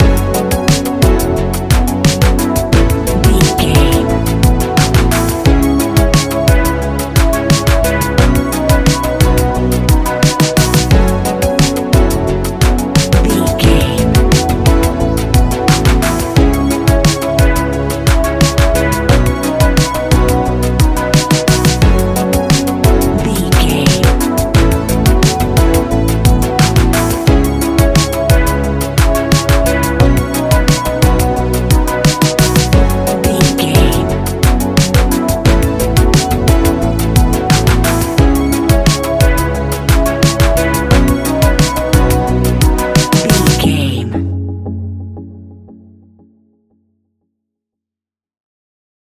Ionian/Major
F♯
ambient
electronic
new age
chill out
downtempo
pads